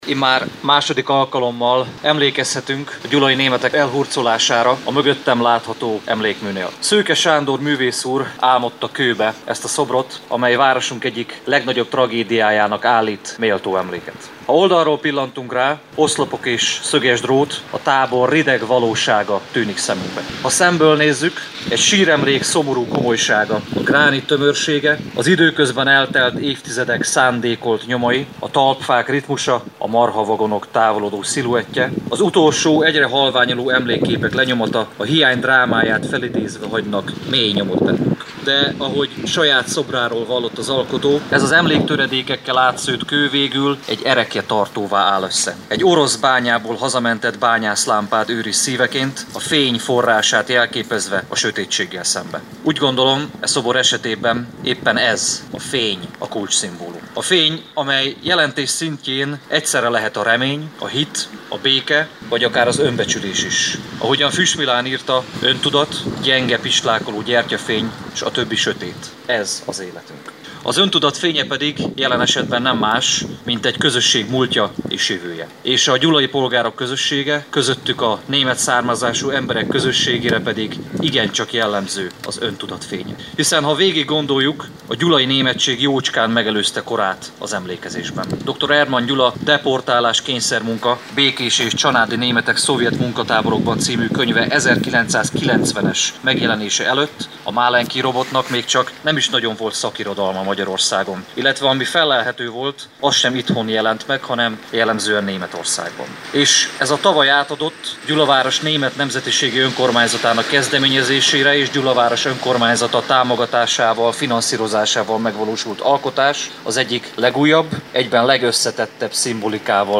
A málenkij robotra elhurcolás 71. évfordulója alkalmából tartottak megemlékezést Gyulán - Körös Hírcentrum